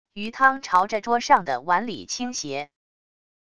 鱼汤朝着桌上的碗里倾斜wav音频